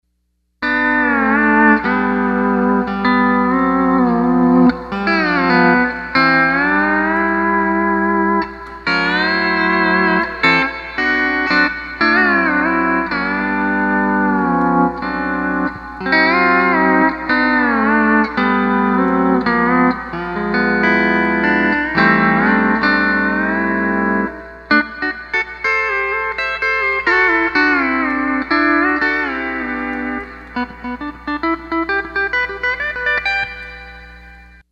MSA Legend, 16.6K Truetones
15" cable to Boss RV-5
RV-5 Settings: Elevel 11:00, Tone 1:00, Time 1:30, Mode Hall
15' cable to Fender Steel King with stock speaker